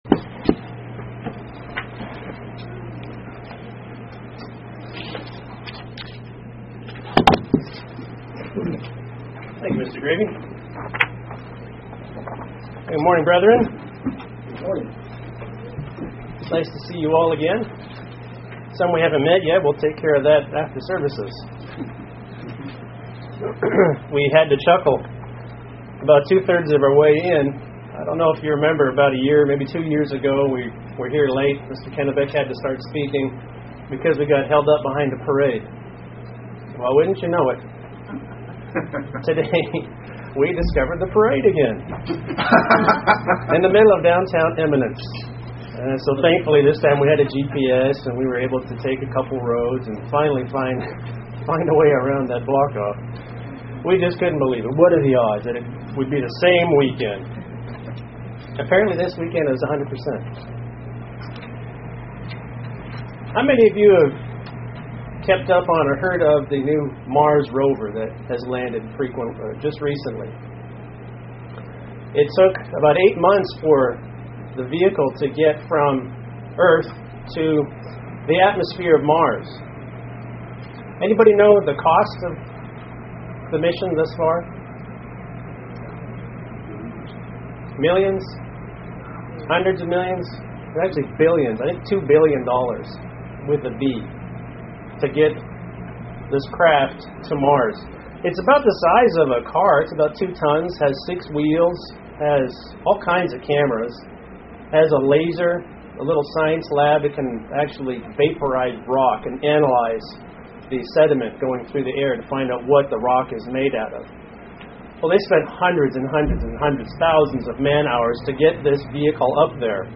Split sermon: Reduce the distance between you and God, by getting closer to God. A few key points on how to get closer to God